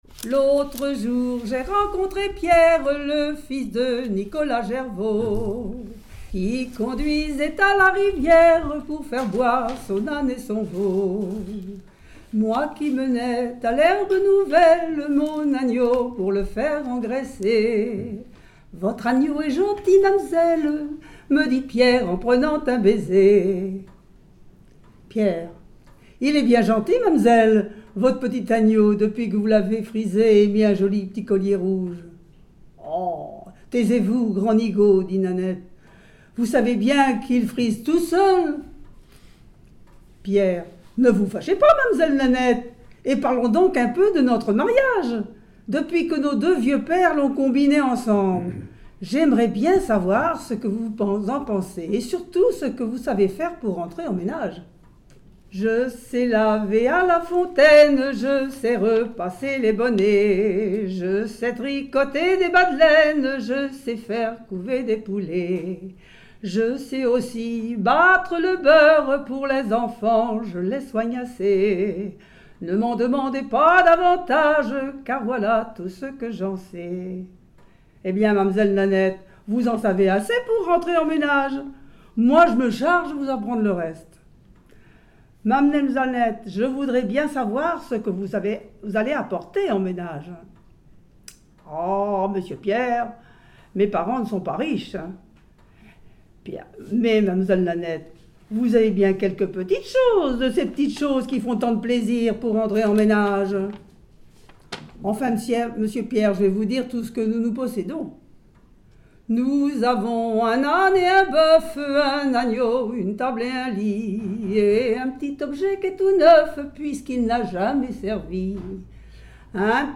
Mémoires et Patrimoines vivants - RaddO est une base de données d'archives iconographiques et sonores.
Genre dialogue
Pièce musicale inédite